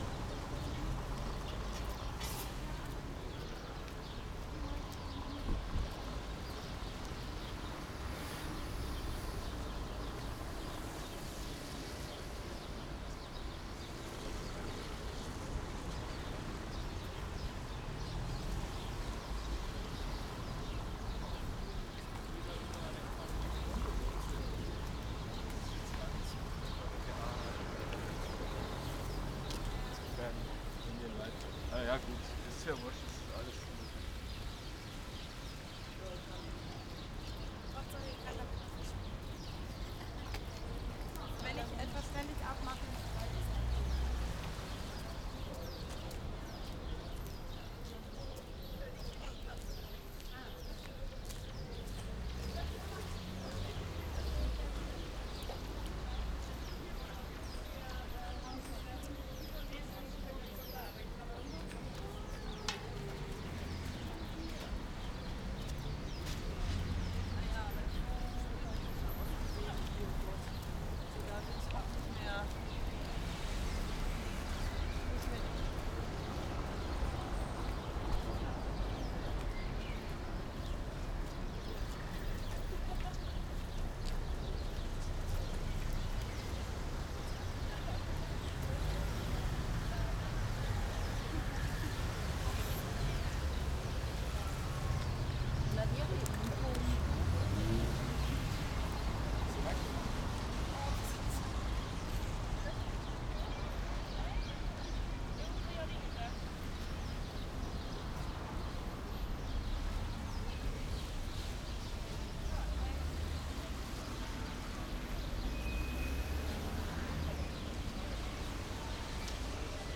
Bridge - listen with headphones
Bridge-fragment.mp3